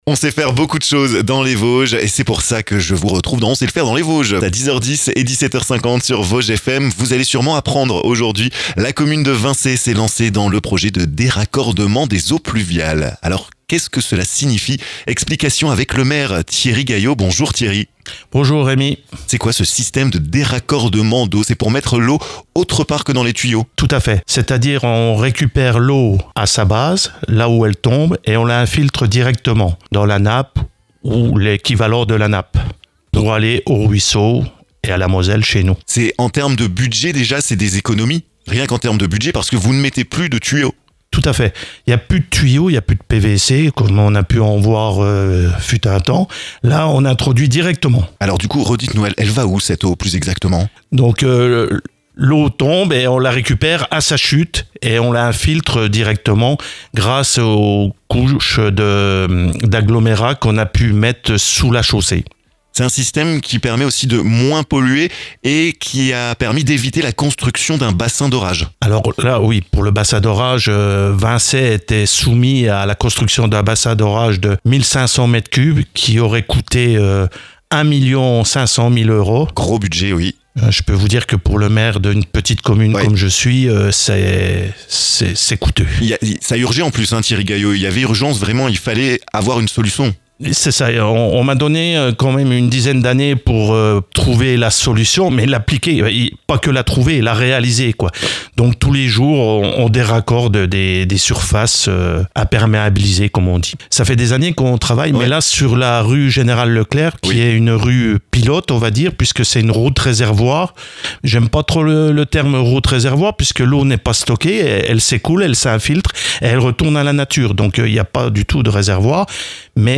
Thierry Gaillot, maire de Vincey, vous explique ce système de déraccordement des eaux pluviales que la commune a mis en place, et qui représente des économies énormes! Un exemple que d'autres communes devraient suivre selon le maire de Vincey!